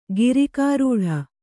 ♪ girikārūḍha